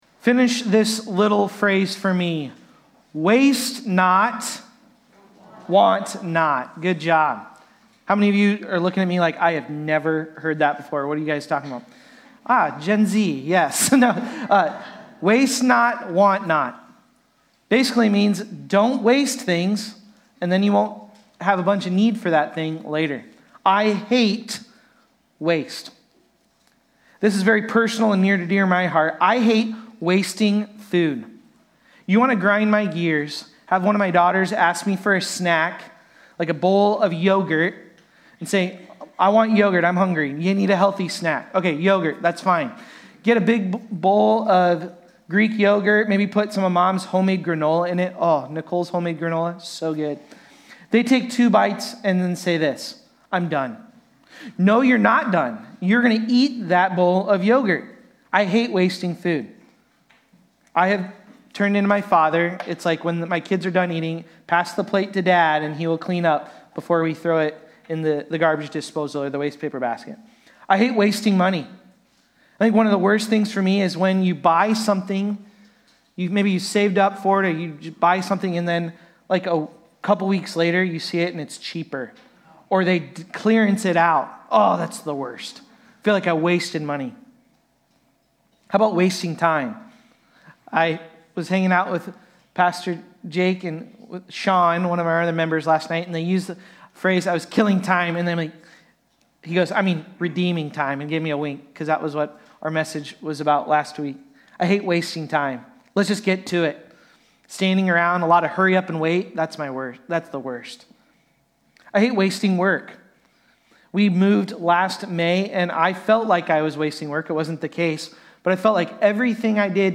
Galatians-4.8-11-Sermon-Audio.mp3